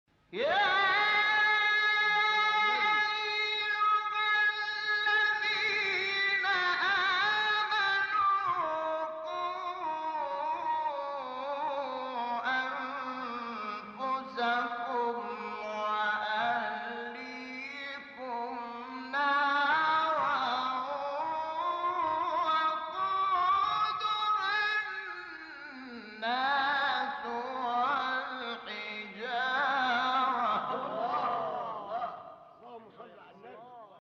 گروه شبکه اجتماعی: مقاطعی از قاریان مصری که در مقام رست اجرا شده است، می‌شنوید.
مقام رست